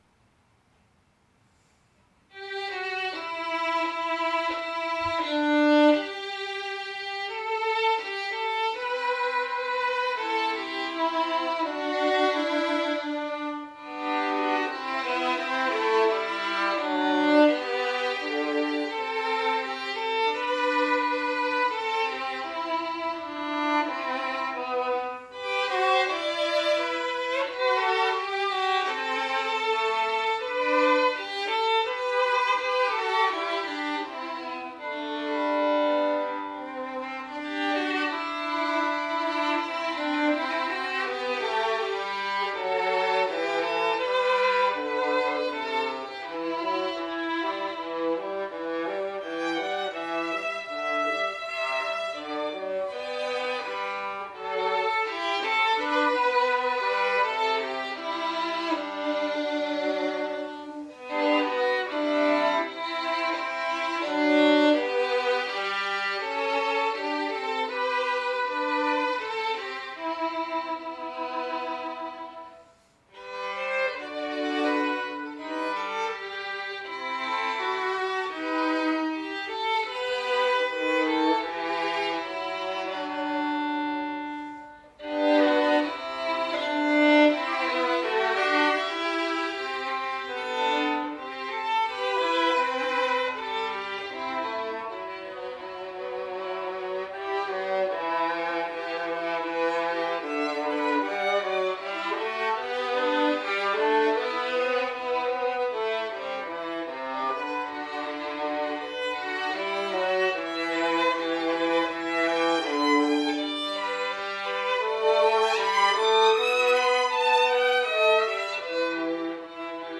arranged here for violin and viola only